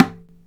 MADAL 2A.WAV